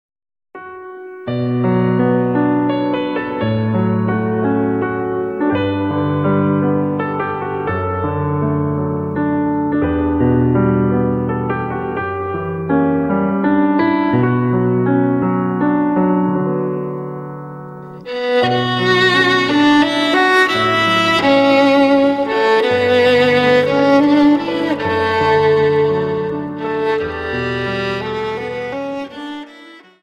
Dance: Slow Waltz 28